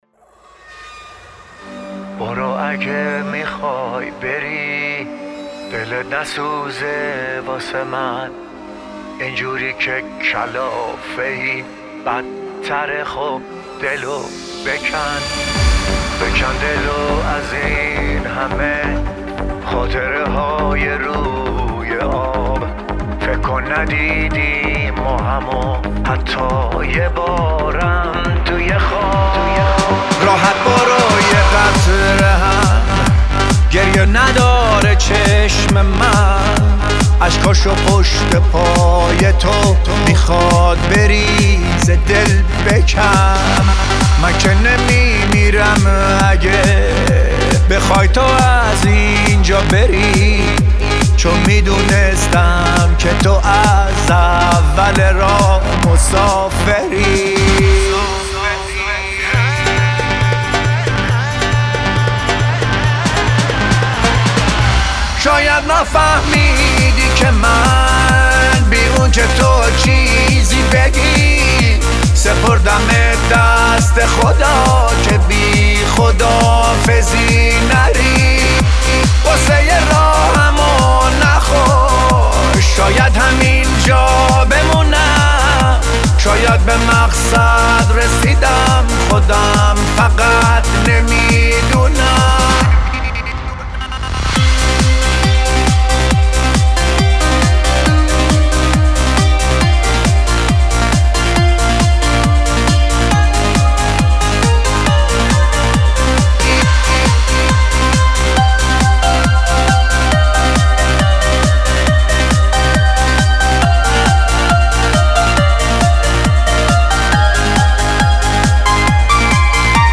بیس دار ریمیکس